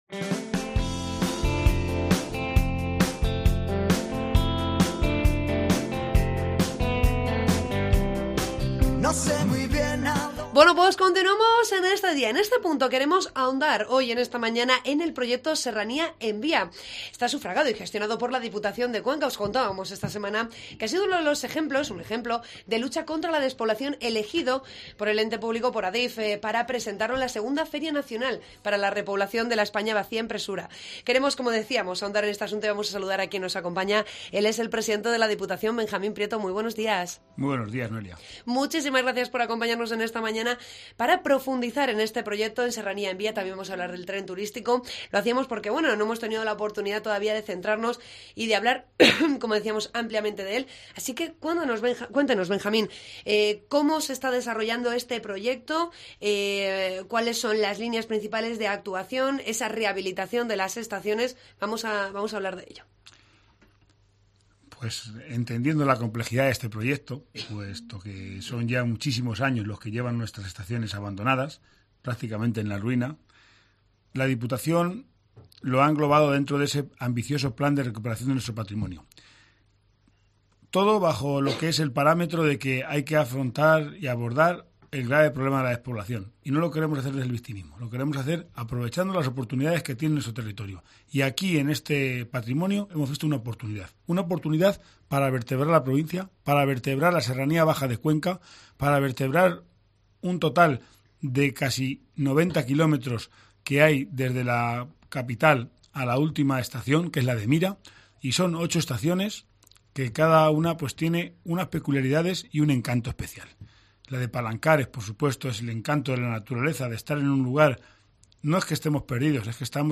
Entrevista a Benjamín Prieto sobre el proyecto 'Serranía en Vía'
AUDIO: Hablamos con el presidente de la Diputación en Herrera en COPE Cuenca